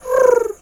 Animal_Impersonations
pigeon_2_call_calm_01.wav